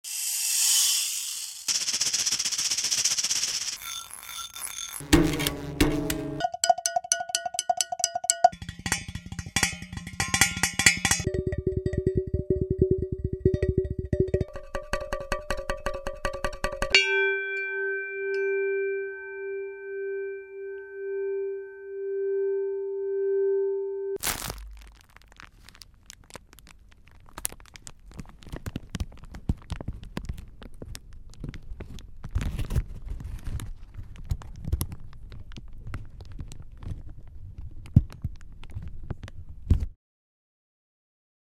Quelques explorations de corps sonores
Les sons que vous venez d'entendre sont tous différents, mais proviennent tous d'un même phénomène.
En effet, chacun d'entre d'eux se font entendre par une mise en vibration.
Cette onde a ensuite été transformée par un microphone en signal électrique, amplifiée puis enregistrée sur un support[1] .